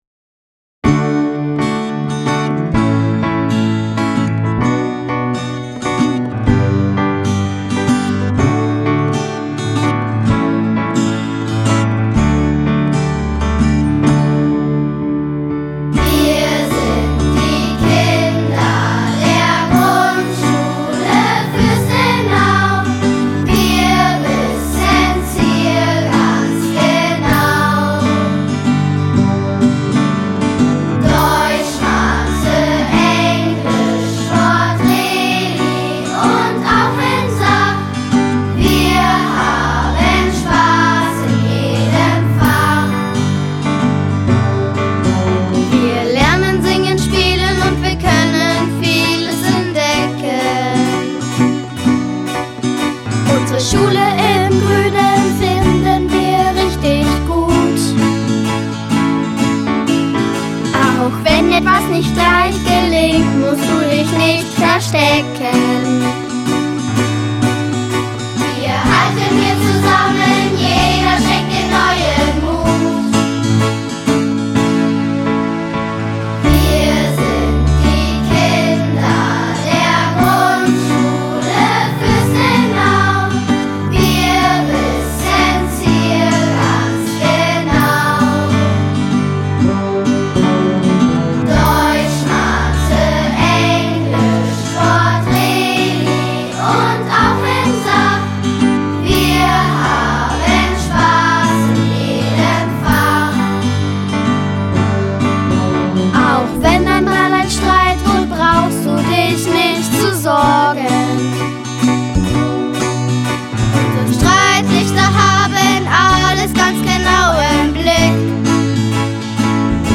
Aufnahme unseres Schulliedes im Tonstudio
Dann ging es für den ganzen Chor gleich zur Sache und der Refrain wurde mehrmals aufggenommen, um dann für das endgültige Ergebnis mehr Fülle zu erhalten. Danach wurden von jeweils zwei Kindern die solistischen Stellen aufgenommen.
Dann fehlten noch die Schlusszeilen der Strophen und die Bridge am Ende, die wieder von allen eingesungen wurden.